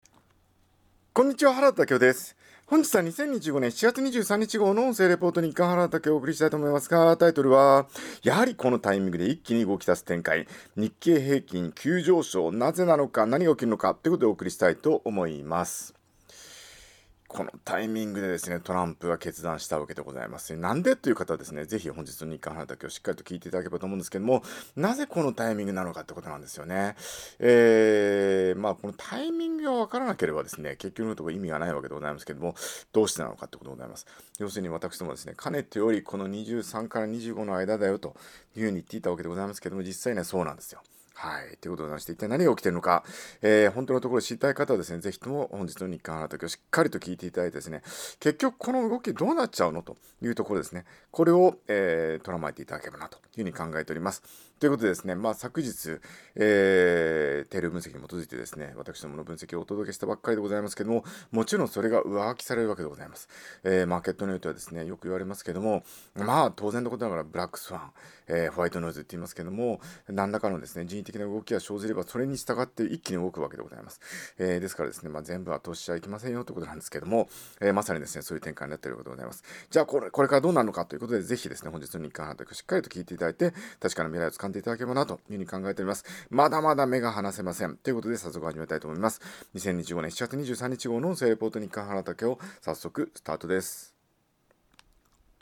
音声レポート